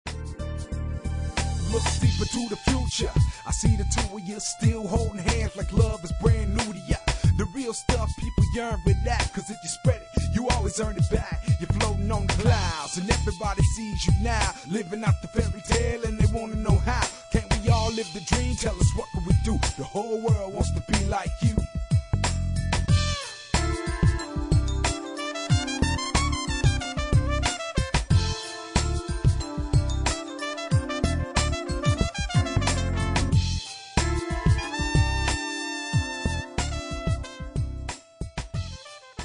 Trumpet Sounds of My Students: